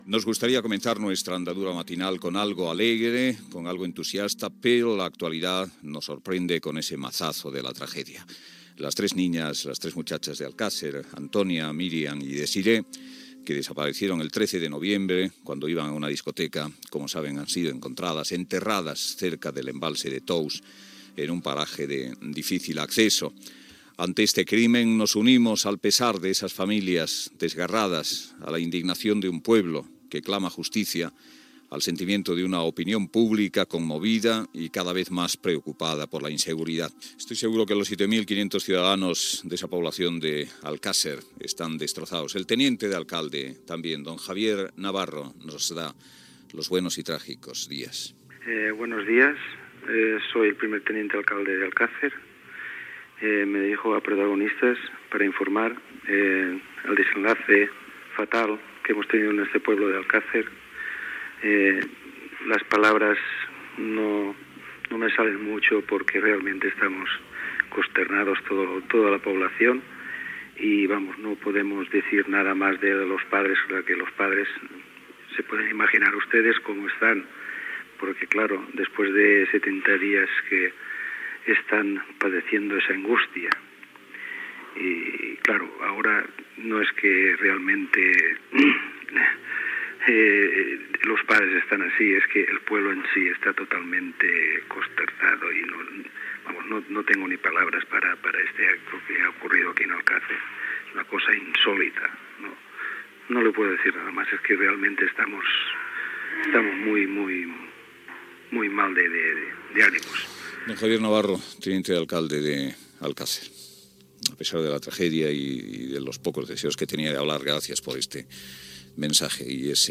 Inici del programa el dia que es van trobar els cossos de les nenes d'Alcásser. Salutació del tinent d'alcalde Javier Navarro.
Info-entreteniment